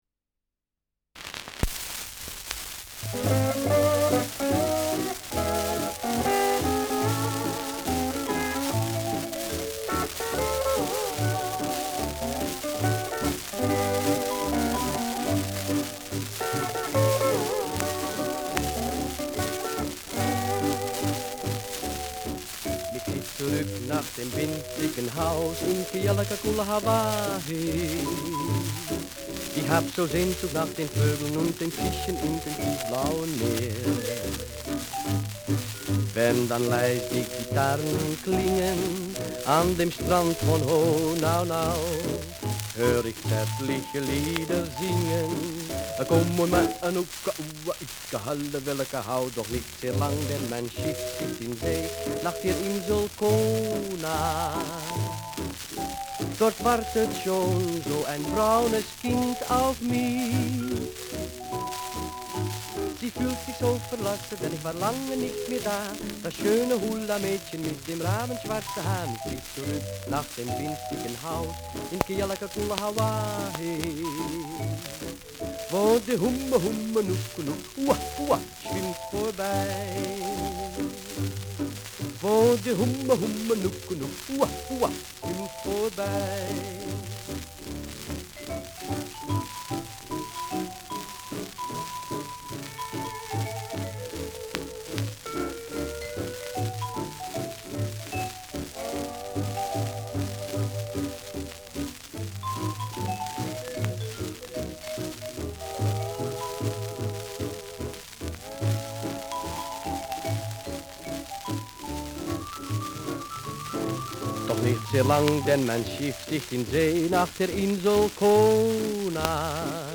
Schellackplatte
Tonrille: Kratzer Durchgehend Leicht